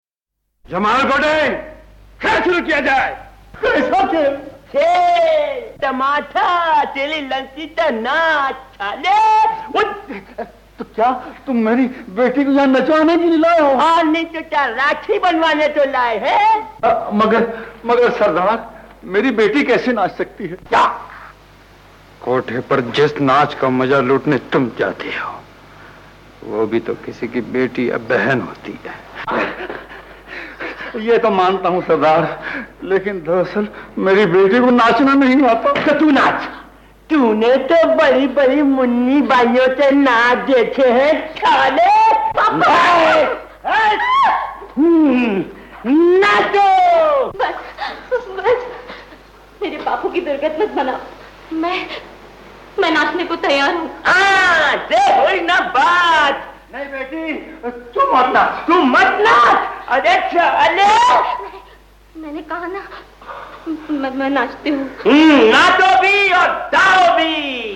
[Artist: Dialogue ]